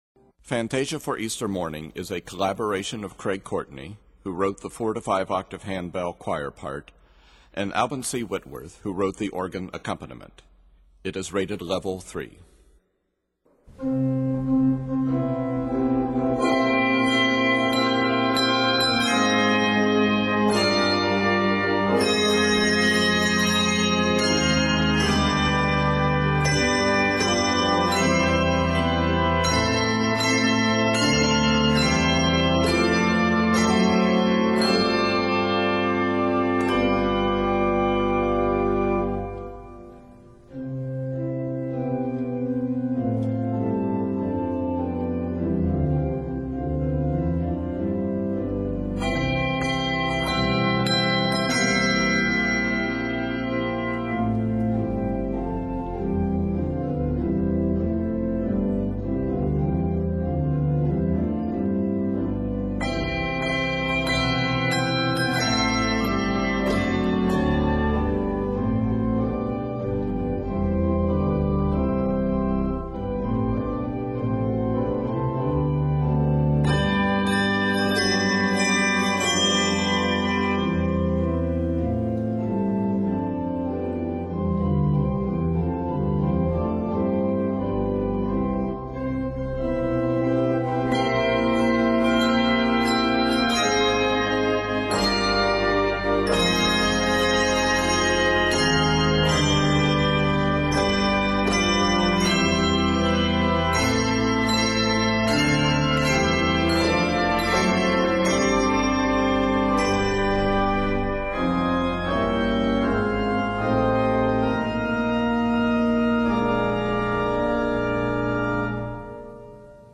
triumphant arrangement for Easter Sunday Morning
Octaves: 4-5